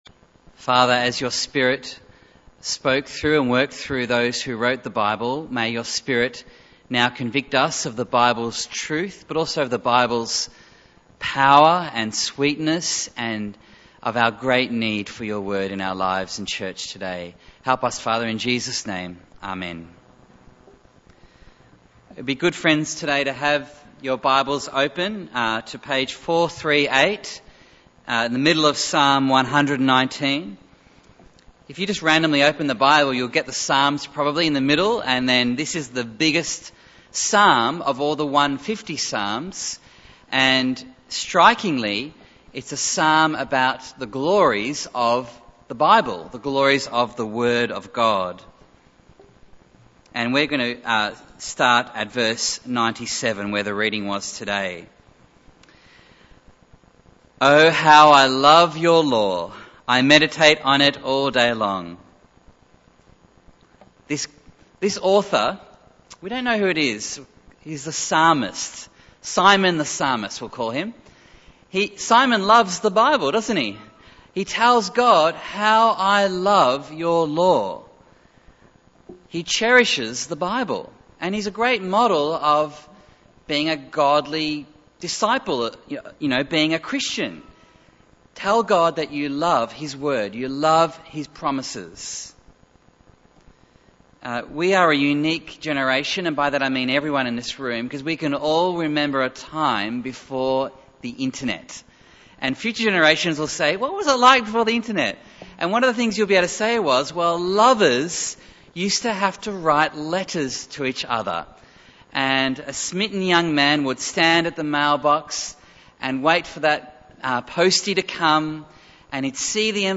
Bible Text: Psalm 119:97-112 | Preacher